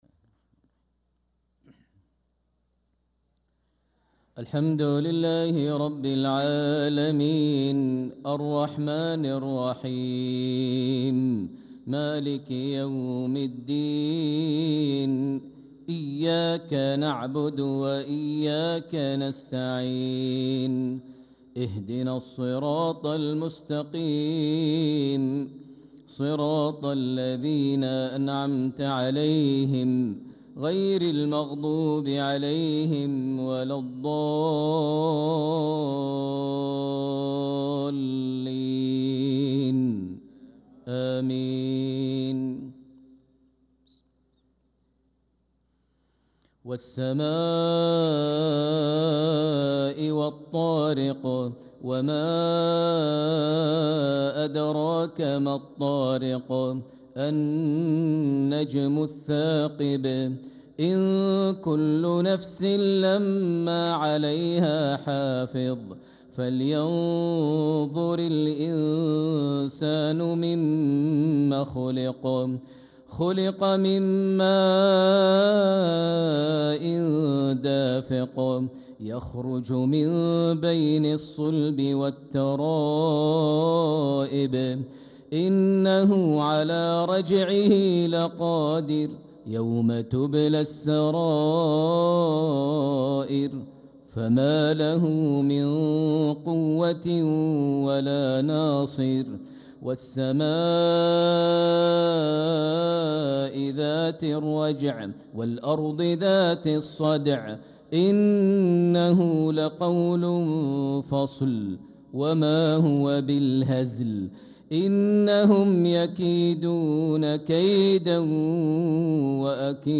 صلاة المغرب للقارئ ماهر المعيقلي 23 ربيع الأول 1446 هـ
تِلَاوَات الْحَرَمَيْن .